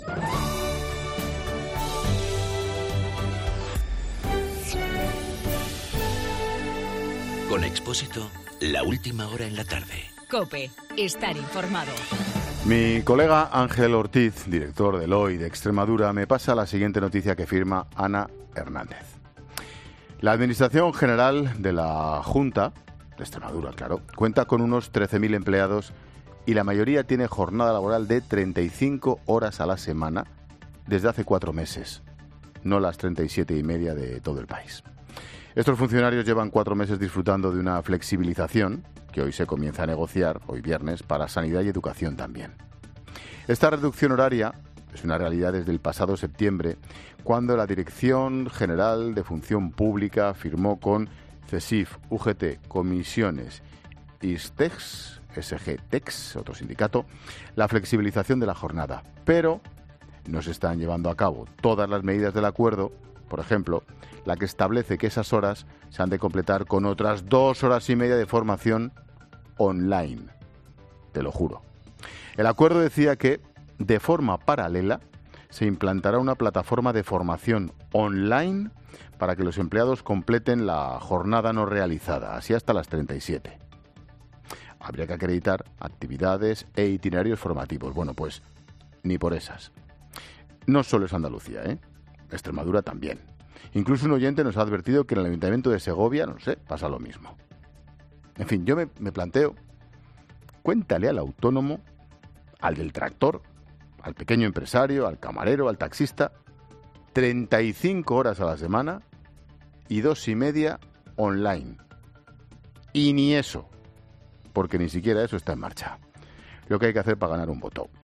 Monólogo de Expósito
El comentario de Ángel Expósito sobre los horarios en la Junta de Extremadura, según le han informado desde el diario "Hoy" de Extremadura.